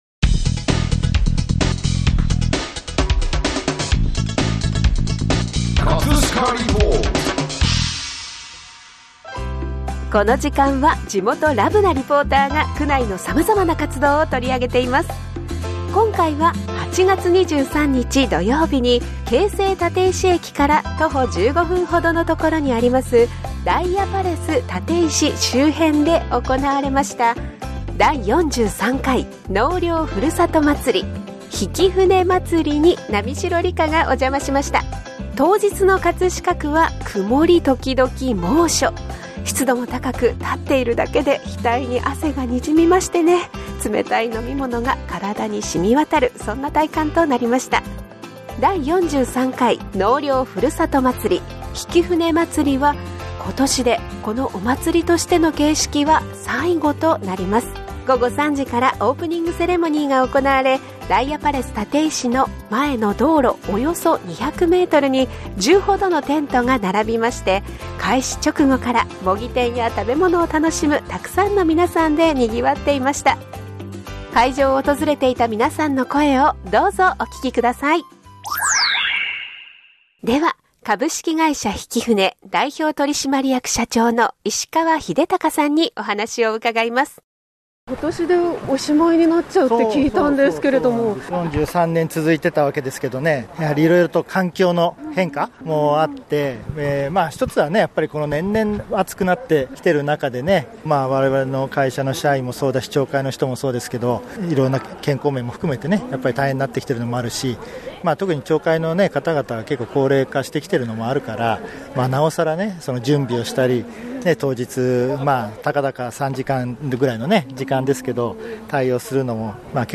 【葛飾リポート】 葛飾リポートでは、区内の様々な活動を取り上げています。
午後3時からオープニングセレモニーが行われ、ダイアパレス立石前の道路約200ｍに10ほどのテントが並び、開始直後から模擬店や食べ物を楽しむ沢山の皆さんでにぎわっていました。 会場を訪れていた皆さんの声をどうぞお聴きください。